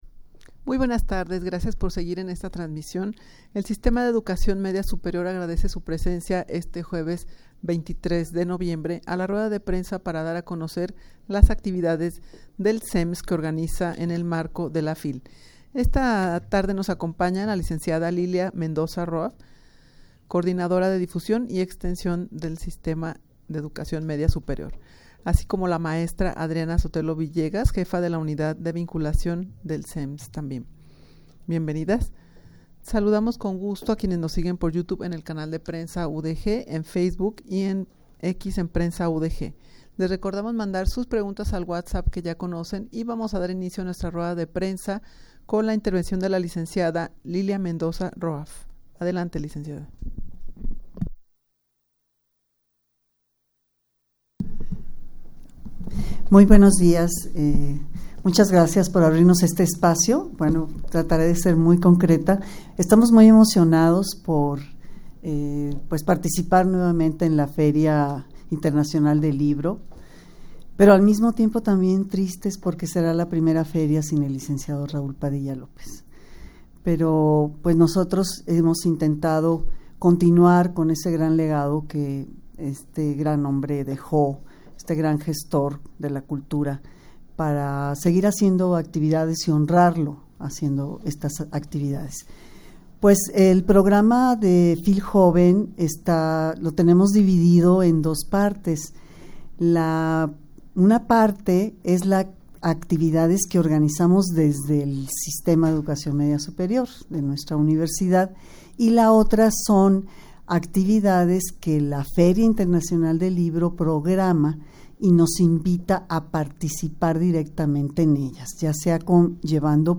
rueda-de-prensa-para-dar-a-conocer-las-actividades-del-sems-que-organiza-en-el-marco-de-fil.mp3